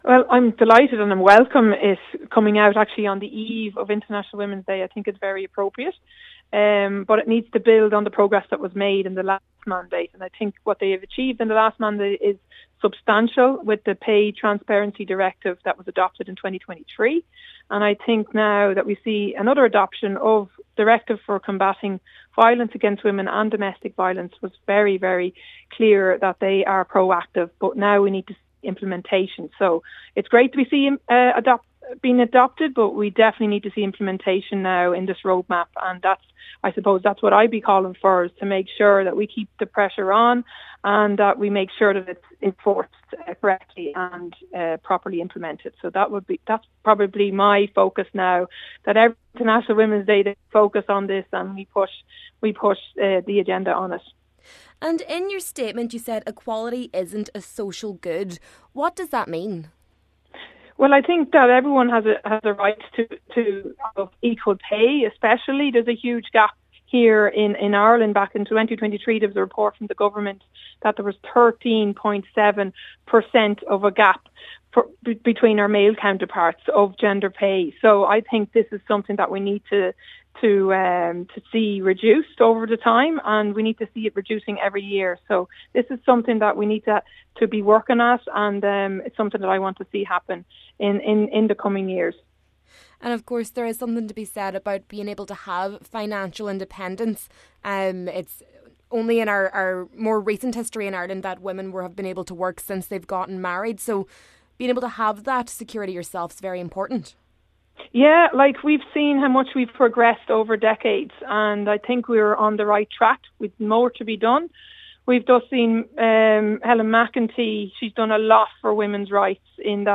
MEP Carberry says Ireland needs to maintain the progress made in recent years: